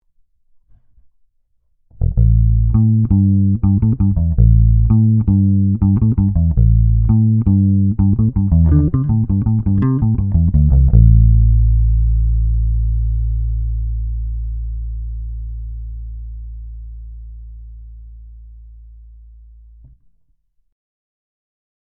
The Sirena Short Scale comes stock with two fat AlNiCo 5 single coils.
This pickup configuration is reminiscent of many classic short scale basses, and is perfect for getting a wide variety of versatile bass tones.
Shorty-Demo-Both-Pickups.mp3